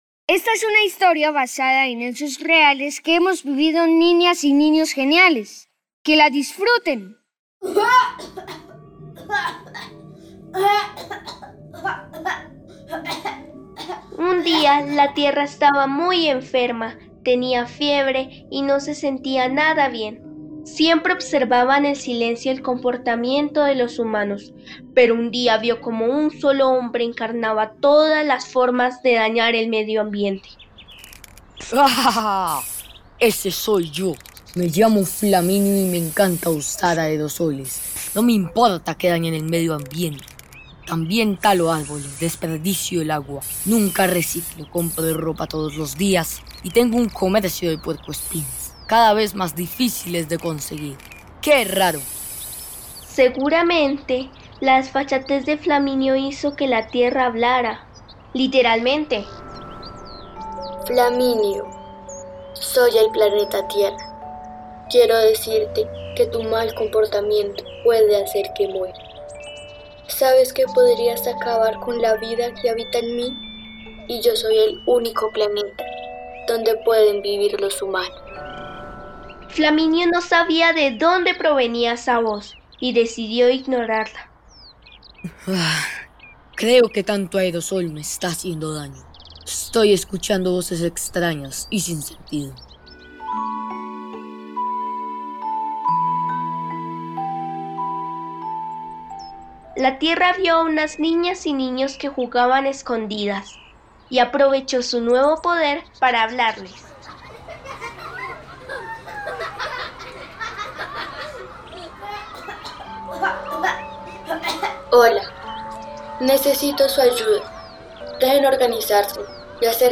Radiocuento - Flaminio, la tierra y los niños - Pódcast Geniales para niños | RTVCPlay